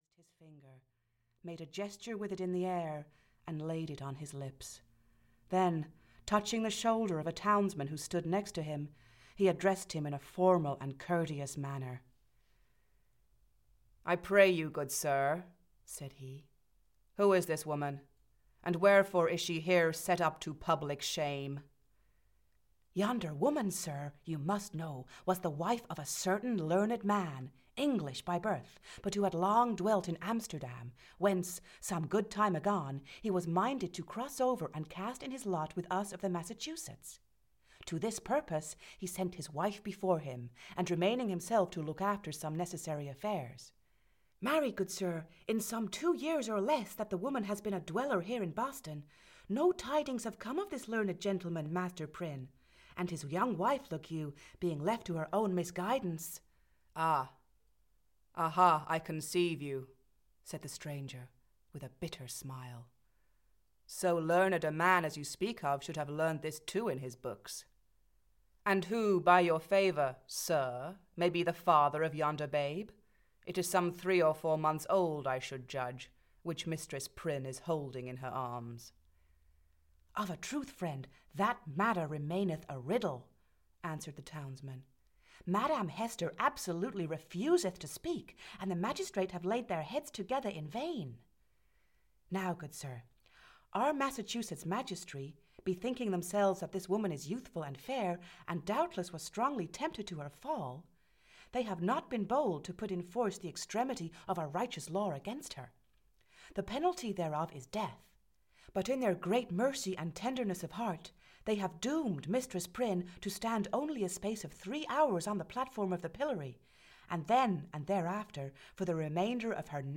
Audio kniha